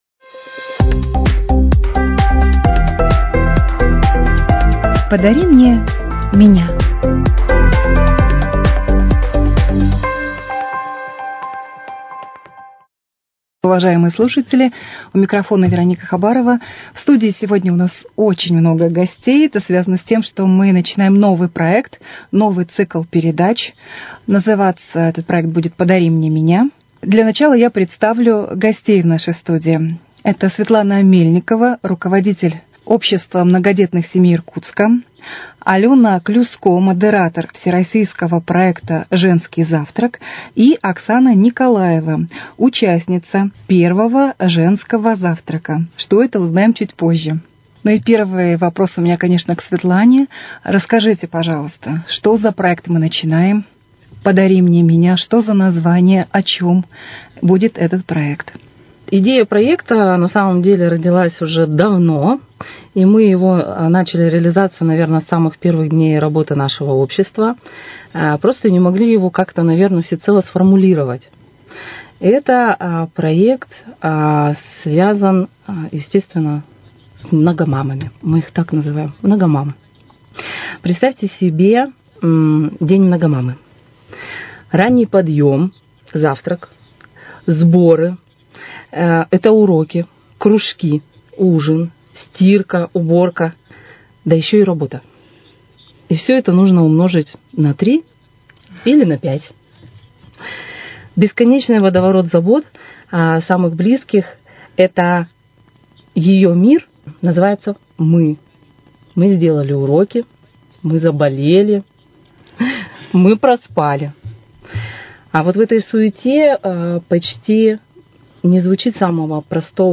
Актуальное интервью: Общероссийский проект для многодетных мам "Женский завтрак" стартовал в Иркутске